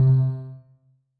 Techmino/media/effect/chiptune/ren_2.ogg at 73145b4e5e5fcc075cf2d7e6ccce37da39f61cae